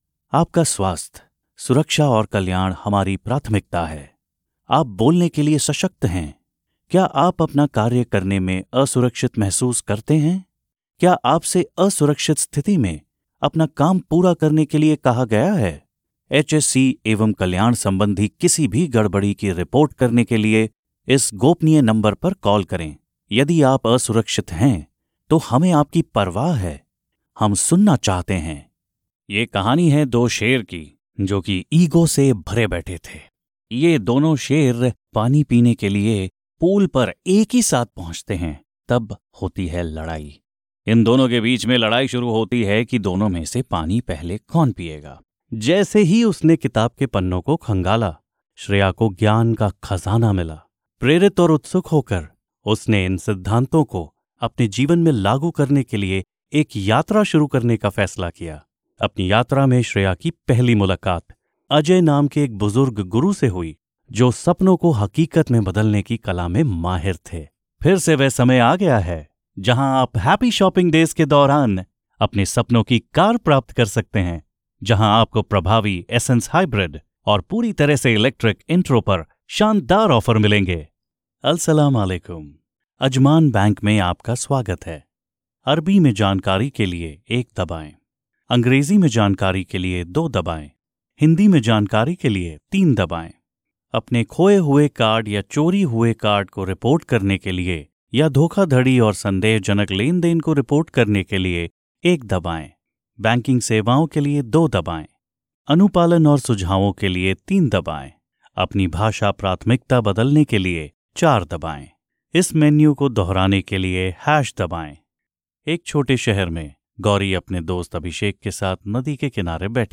Female 20s , 30s , 40s American English (Native) Approachable , Assured , Authoritative , Bright , Bubbly , Character , Cheeky , Confident , Conversational , Cool , Corporate , Deep , Energetic , Engaging , Friendly , Funny , Gravitas , Natural , Posh , Reassuring , Sarcastic , Smooth , Soft , Upbeat , Versatile , Wacky , Warm , Witty , Young Commercial , Corporate , Documentary , Educational , E-Learning , Explainer , IVR or Phone Messaging , Narration , Podcasts , Training